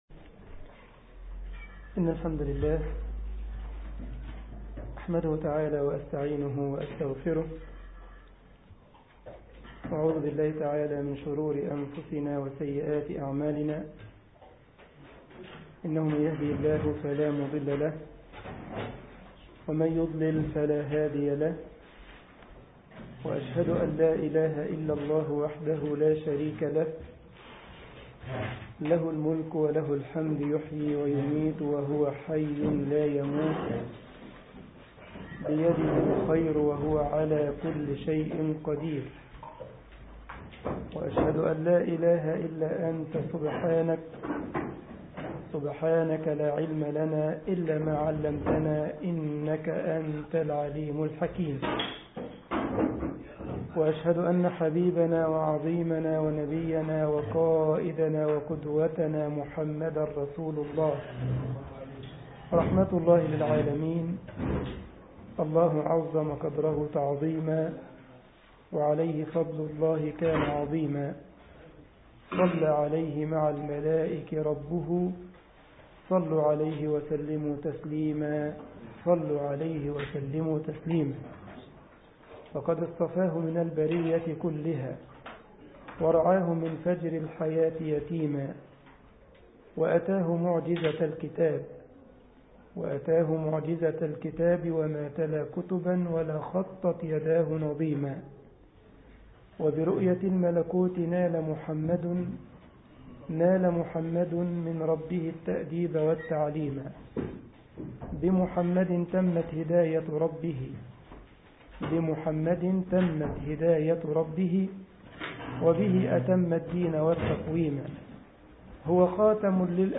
مسجد الجمعية الإسلامية بالسارلند ـ ألمانيا درس الأحد